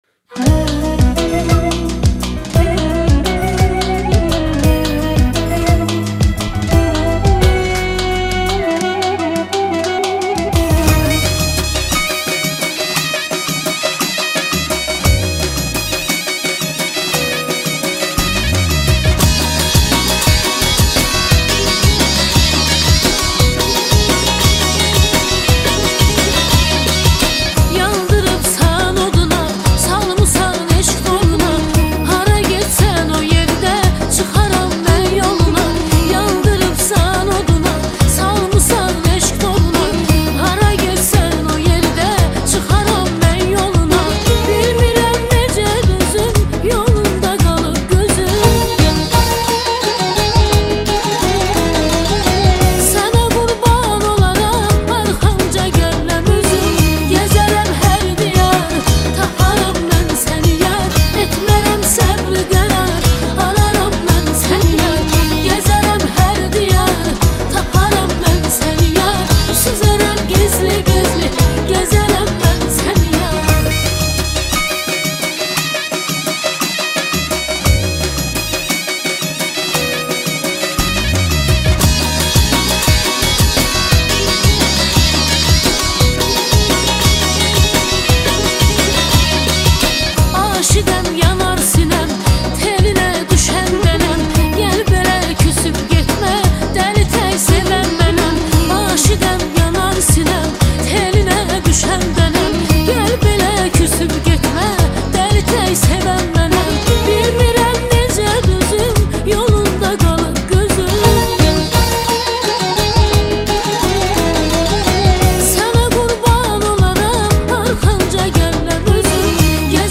آهنگ آذربایجانی